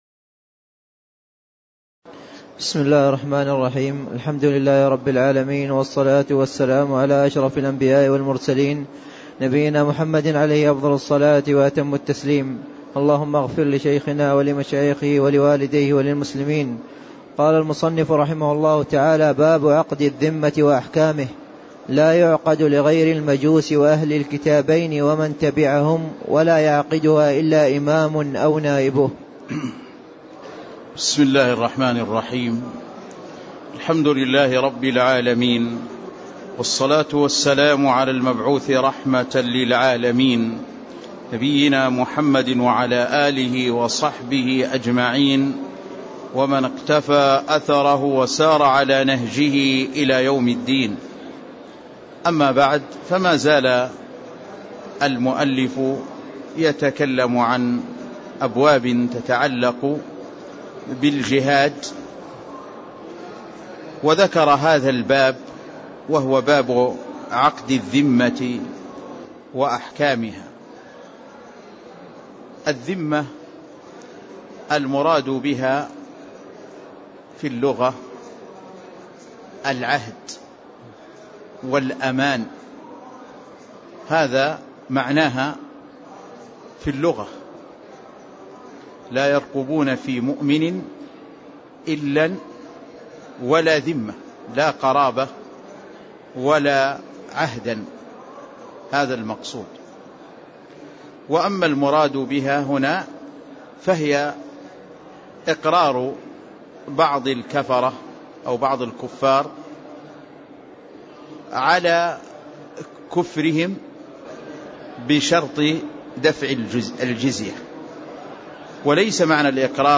تاريخ النشر ٢٦ جمادى الأولى ١٤٣٦ هـ المكان: المسجد النبوي الشيخ